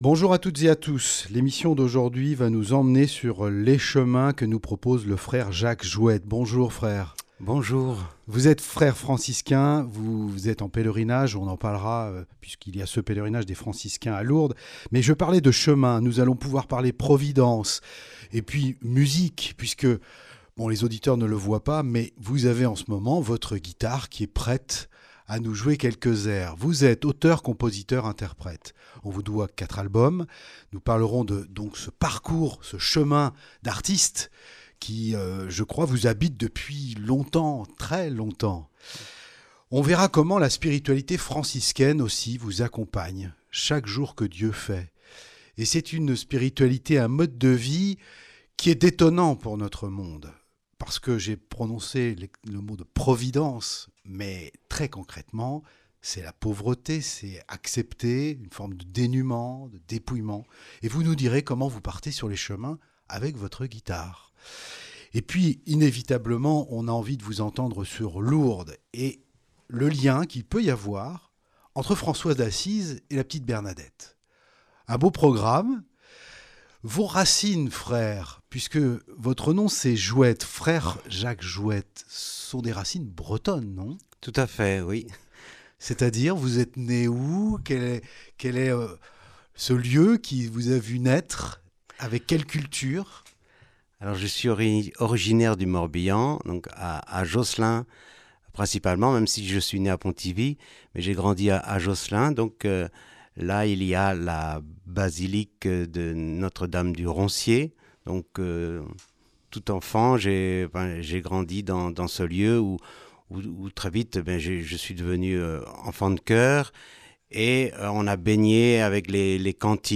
a donné une interview à Lourdes qui a été diffusée sur Radio Présence et que vous pouvez écouter en cliquant sur les 2 photos.